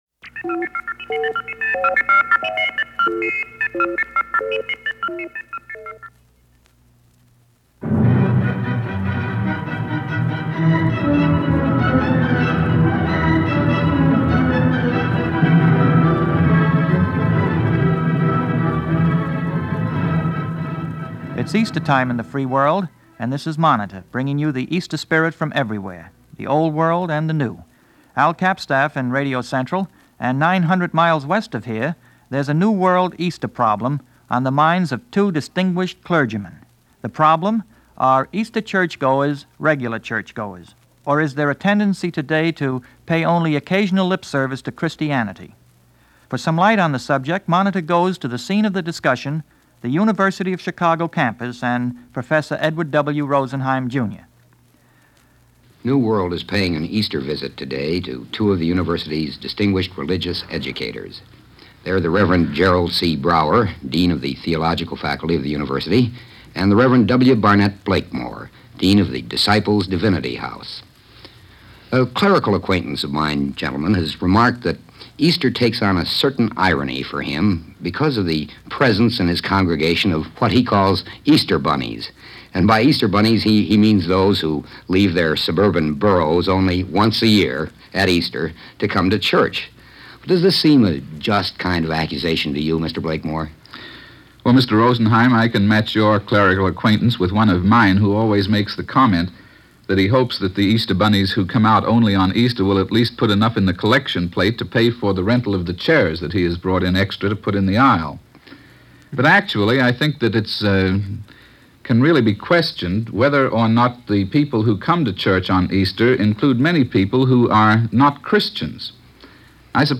Easter 1956 - Past Daily Holiday Gallimaufry - a discussion on the changing views toward Easter and religion in general.